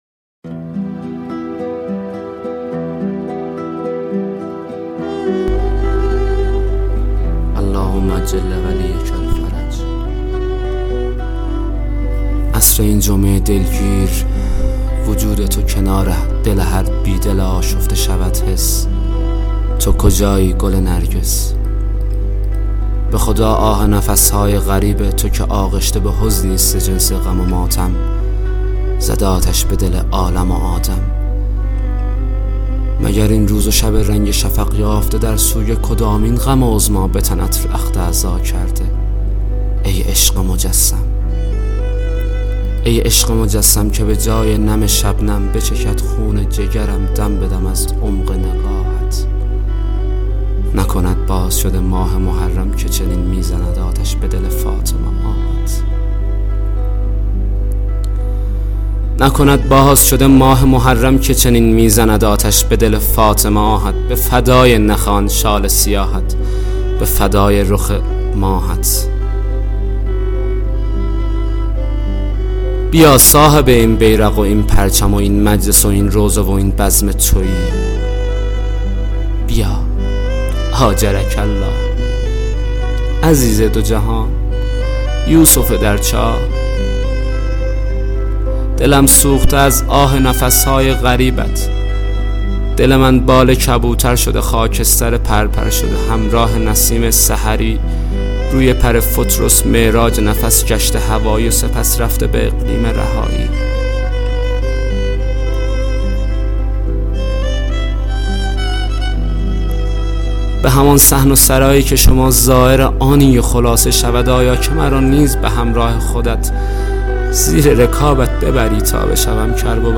دکلمه-ماه-محرم.mp3